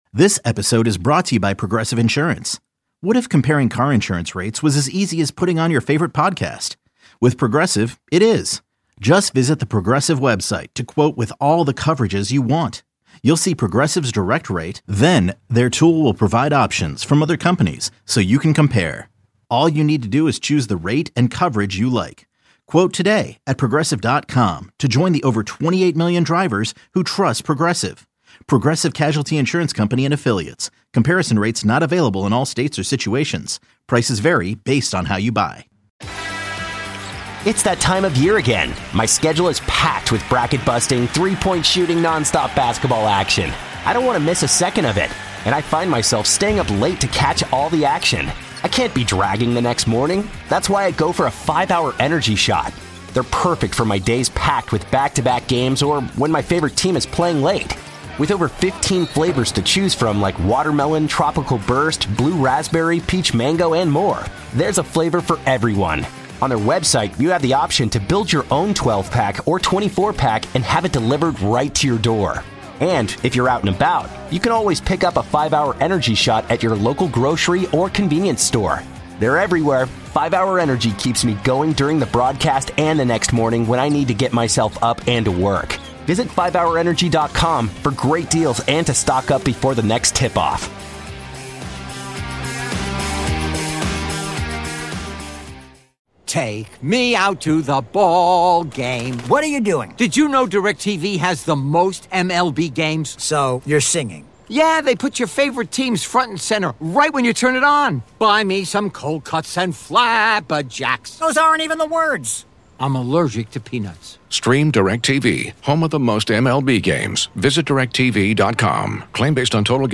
The nightly program has been a fixture on KMOX for many years and features a variety of hosts.